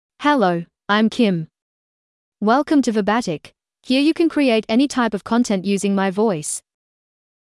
Kim — Female English (Australia) AI Voice | TTS, Voice Cloning & Video | Verbatik AI
FemaleEnglish (Australia)
Kim is a female AI voice for English (Australia).
Voice sample
Listen to Kim's female English voice.
Kim delivers clear pronunciation with authentic Australia English intonation, making your content sound professionally produced.